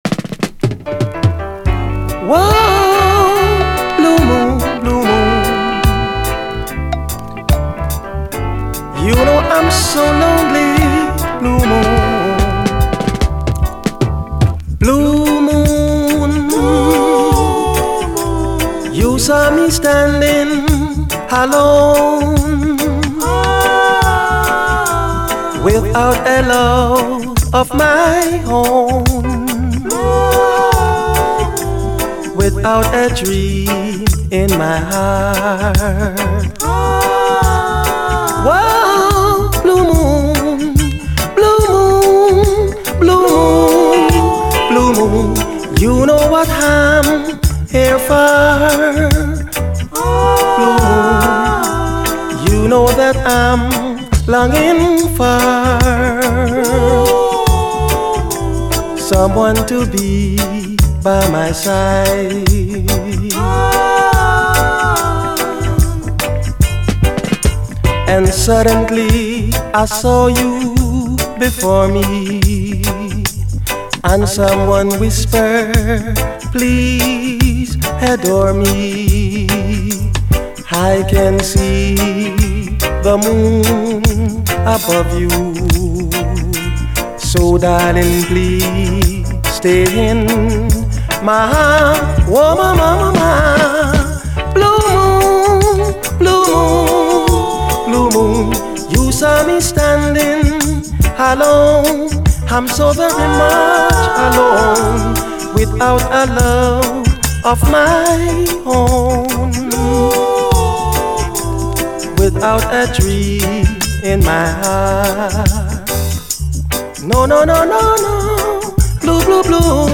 REGGAE
柔らかなバンド演奏、甘く優しいコーラス、何も言うことのないグレイト・ヴァージョンです！
ダブ・ヴァージョンも収録。